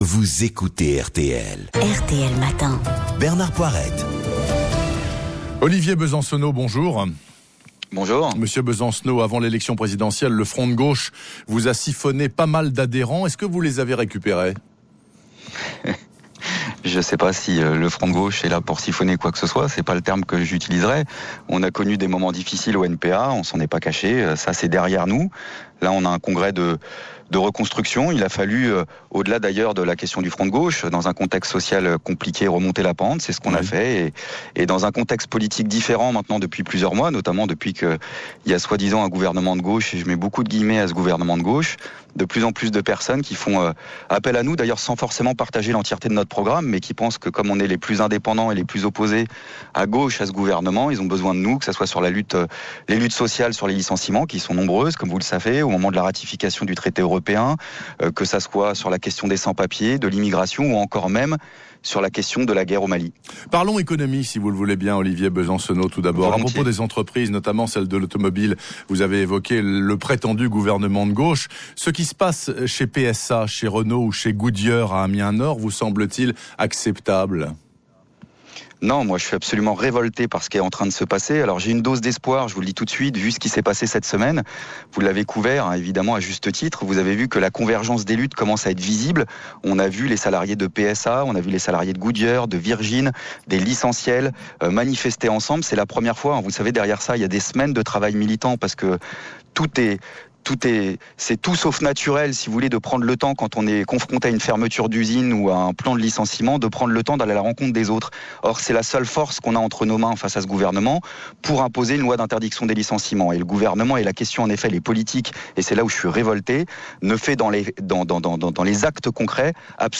L’entretien radiophonique du 2 février 2013 à RTL